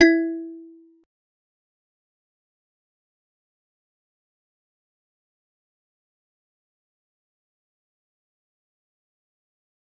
G_Musicbox-E4-pp.wav